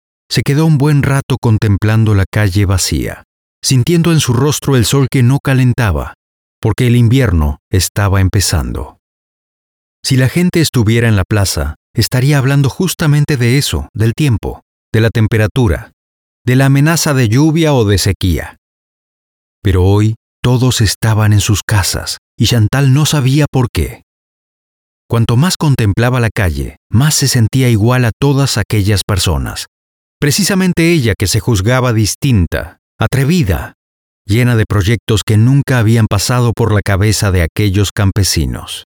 Hörbücher
Professional Home Studio; Isolated floor and acoustic treatment.
Spanisch (Lateinamerika)
Bariton
WarmKonversationErfahrenZuverlässigFreundlich